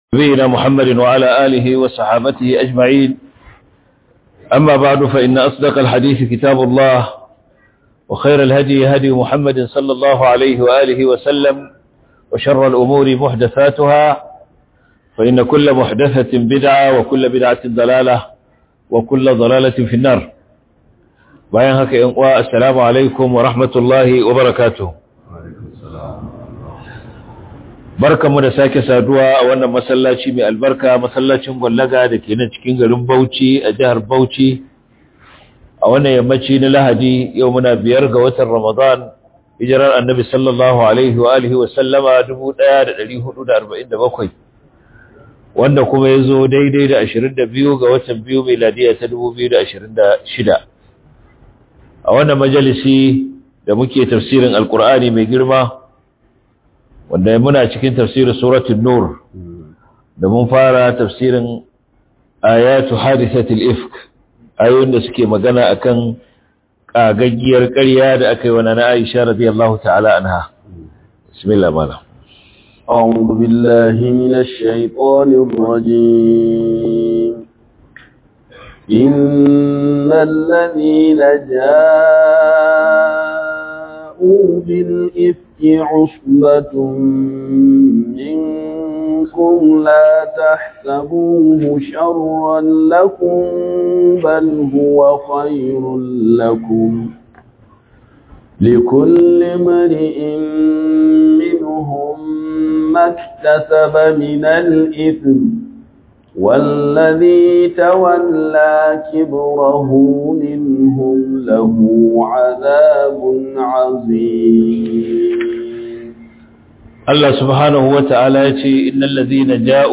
005 Ramadan Tafsir - 1447/2026 Ramadan Tafsir